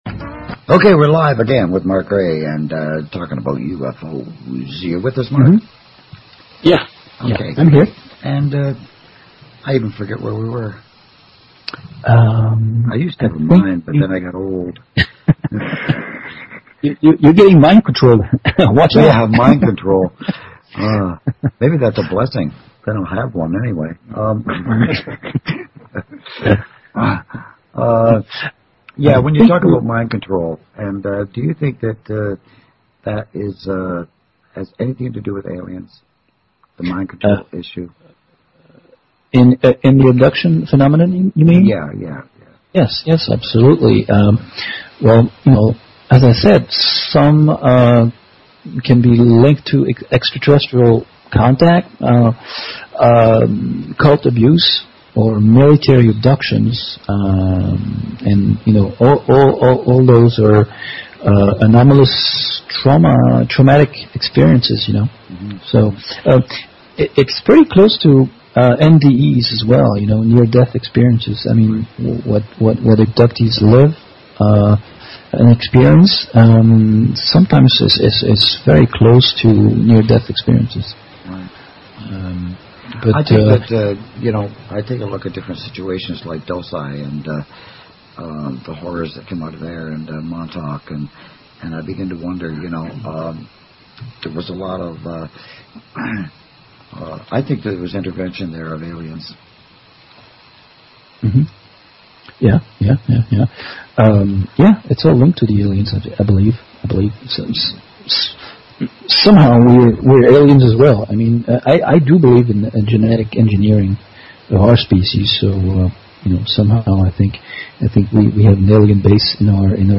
Interview sur Project Freedom Radio Network